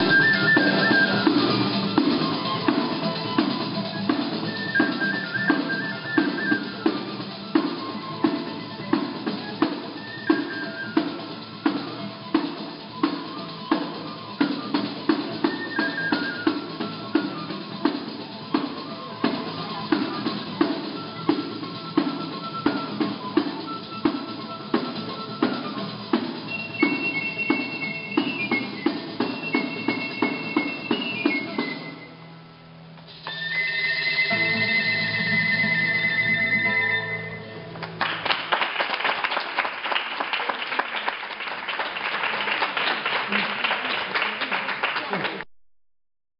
They were truly energetic and had our class all up and dancing with them even before dinner and wine, so you know they were good.  One of their specialties was the tarantella, a frenzied dance that was supposedly invented in the 16th century to help cure victims of tarantism, supposedly caused by tarantula spider bites.
video clip of one of the dances.